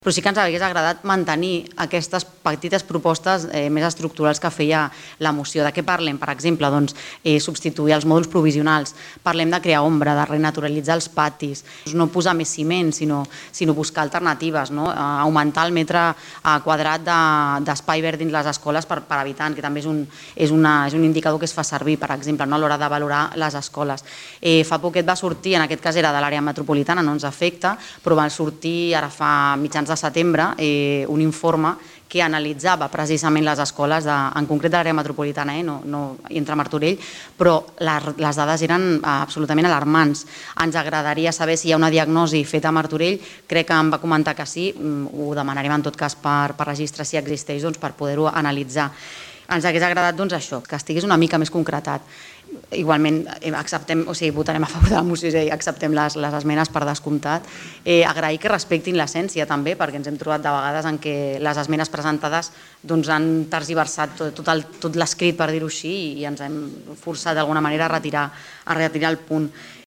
Així es va acordar, per unanimitat, en una moció presentada al Ple Municipal d’aquest dilluns.
Laura Ruiz, portaveu de Movem Martorell